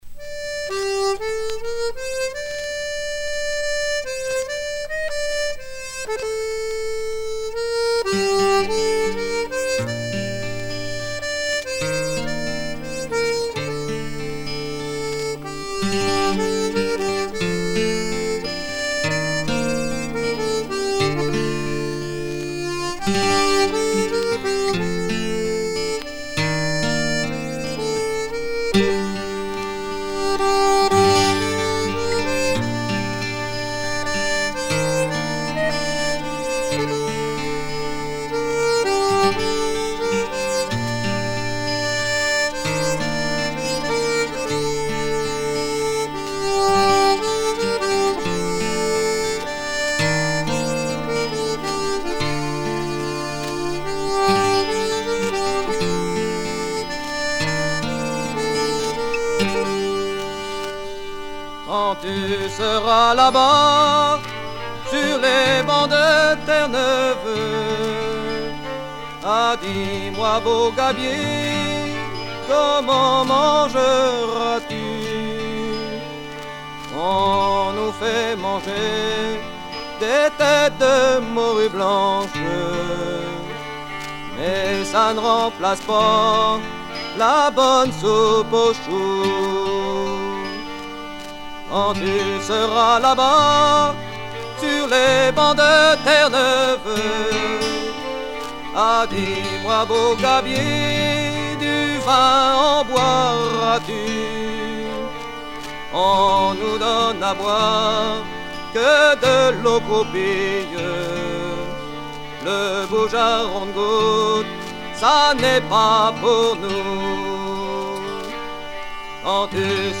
Chants de marins
Pièce musicale éditée